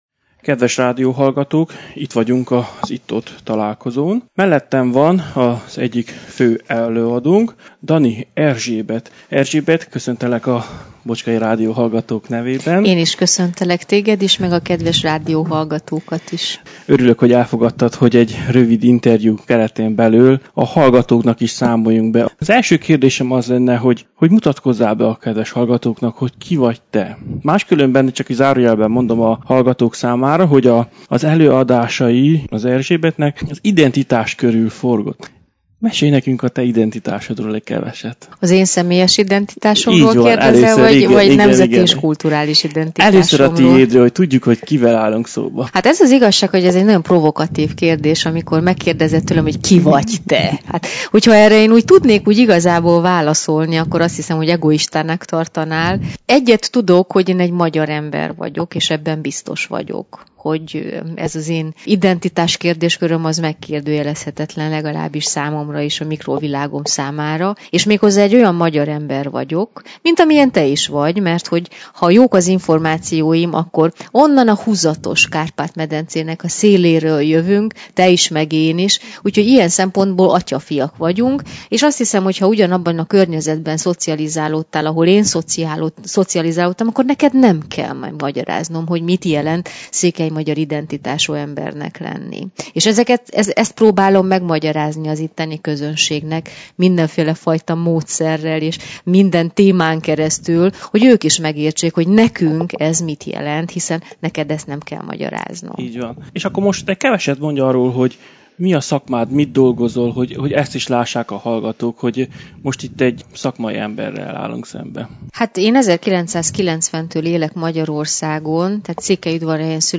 Mindegyik előadása a magyar ember identitása körül forgott. Tehát én is ezzel kapcsolatosan kérdeztem.